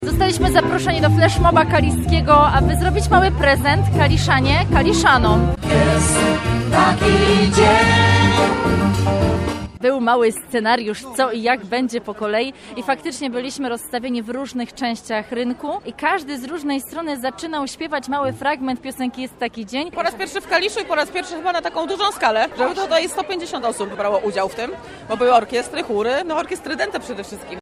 Muzyczny flash mob zaskoczył mieszkańców
Chóry, orkiestry dęte, soliści rozstawieni w różnych punktach miasta i jedna piosenka.
Z okna ratusza, z karuzeli i wśród przechodniów muzycy dołączali do wspólnego wykonania znanego świątecznego przeboju „Jest taki dzień” Czerwonych Gitar.